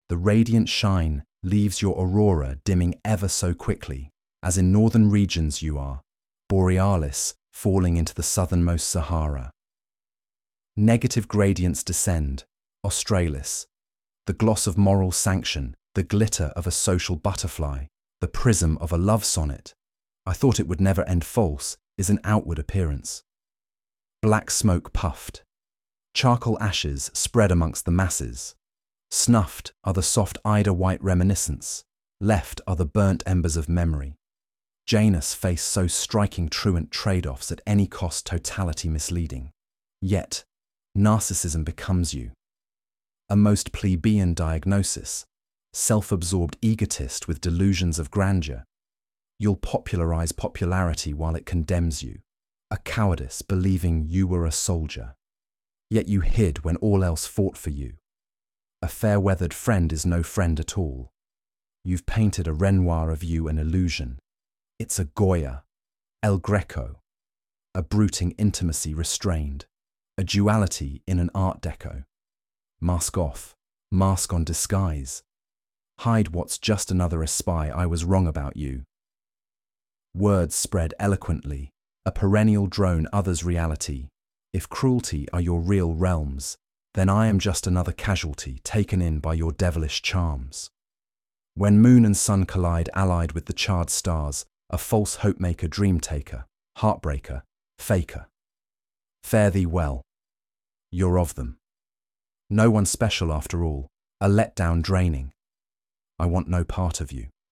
Ha, too much fun, i love the English chap, he does my poetry justice.